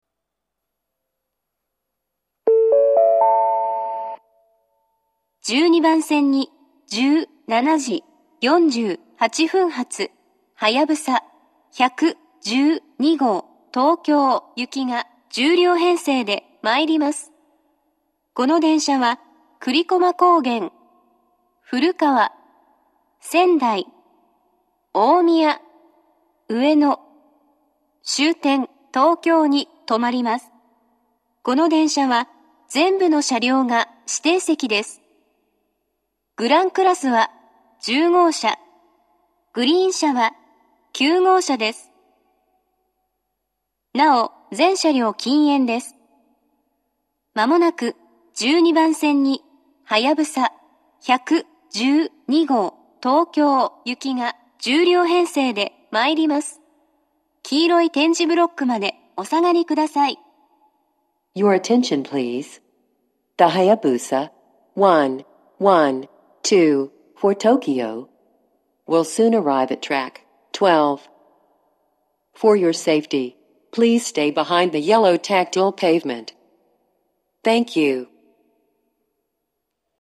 ２０２１年１月下旬頃には、自動放送が合成音声に変更されました。
１２番線接近放送
ホーム上のスピーカーよりも待合室内のスピーカーのほうが音質は良いです。